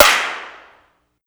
Asap Clap4.wav